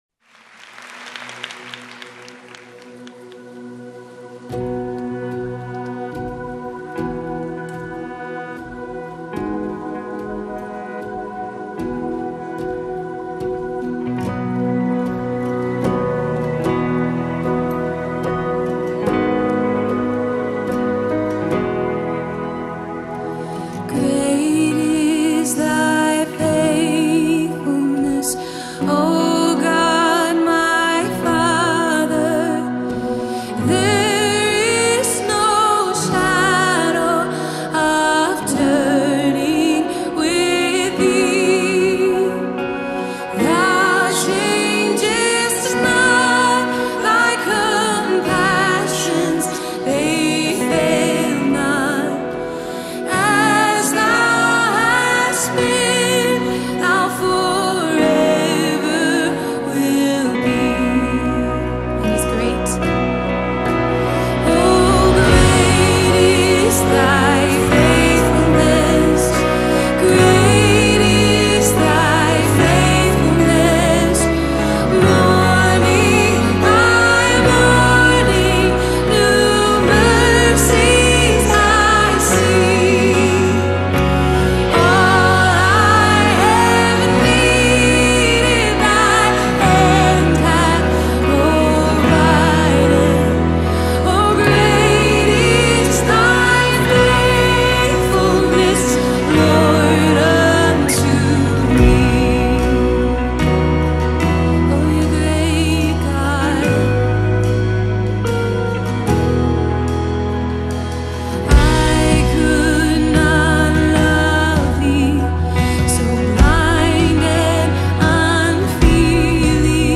Інтро: (запис mp3 на пів тону нижче, у тональності  H)
3124 просмотра 927 прослушиваний 74 скачивания BPM: 74